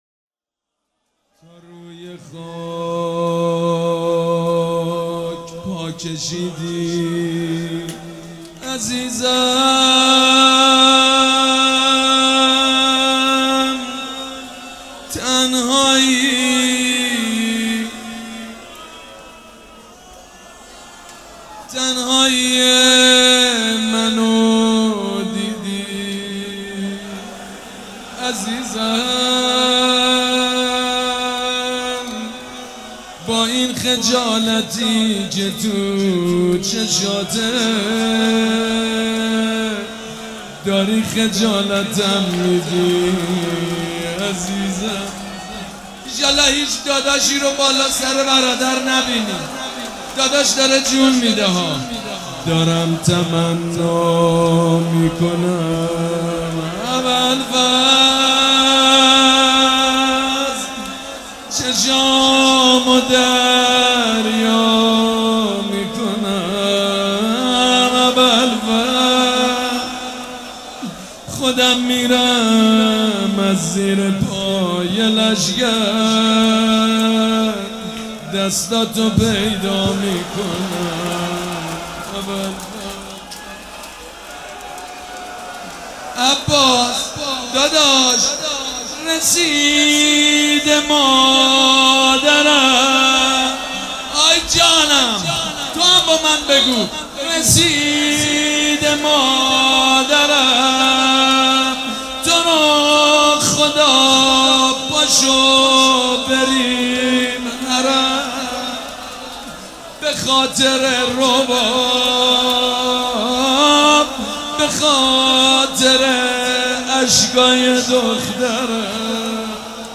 مداحی جدید حاج سید مجید بنی فاطمه شب نهم محرم۹۸ هیات ریحانة الحسین تهران یکشنبه 17 شهریور ۱۳۹۸